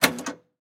flipper.mp3